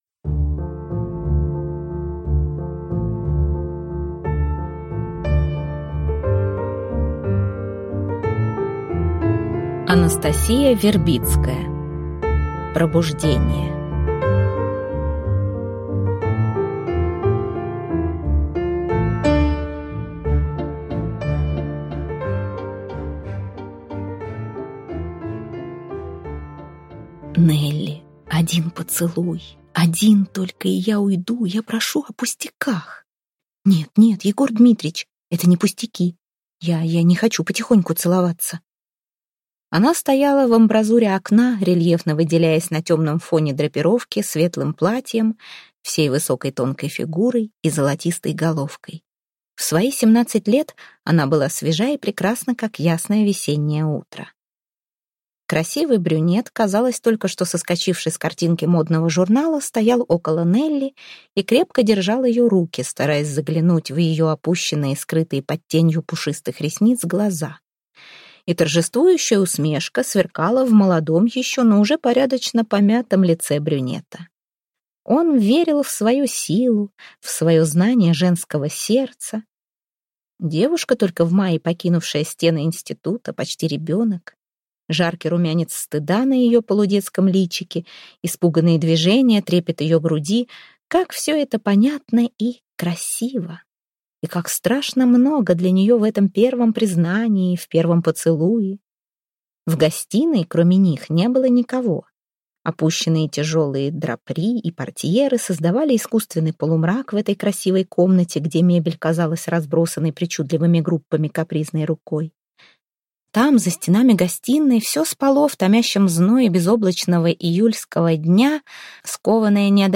Аудиокнига Пробуждение | Библиотека аудиокниг